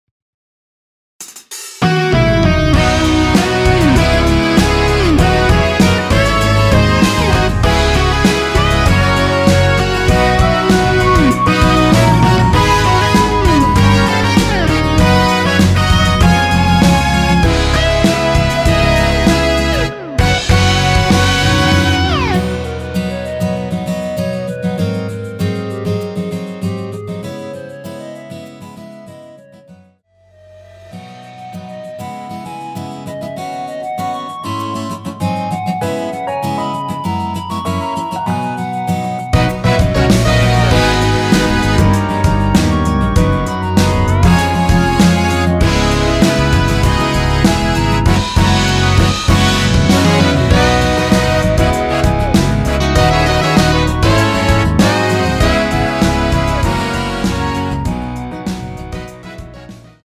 원키에서(-1)내린 멜로디 포함된 MR입니다.(미리듣기 확인)
멜로디 MR이라고 합니다.
앞부분30초, 뒷부분30초씩 편집해서 올려 드리고 있습니다.